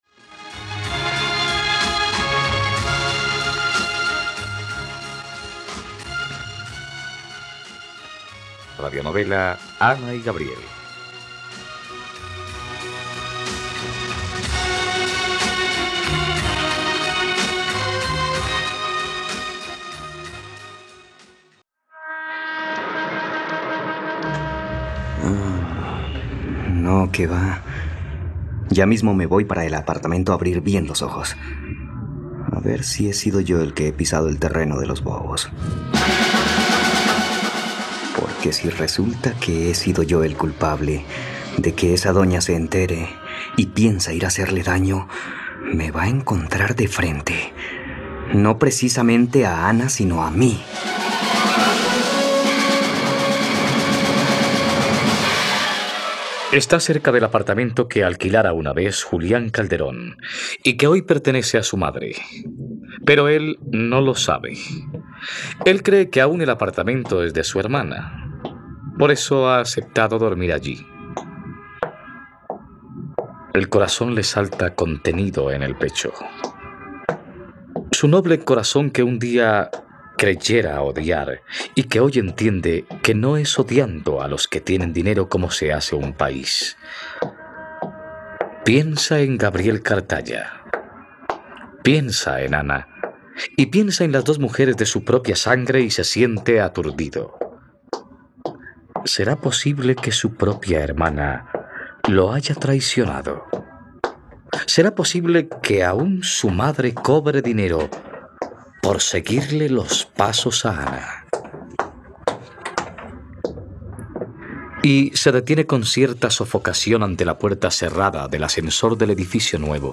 Ana y Gabriel - Radionovela, capítulo 112 | RTVCPlay